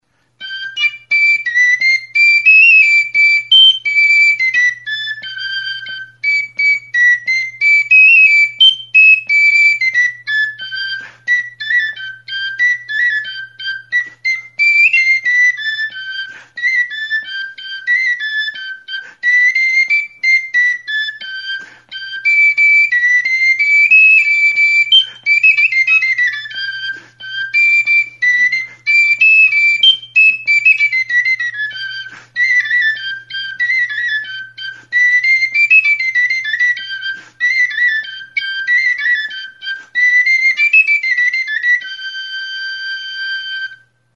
TXISTUA; POXPOLIN; TXIRULA | Soinuenea Herri Musikaren Txokoa
Enregistré avec cet instrument de musique.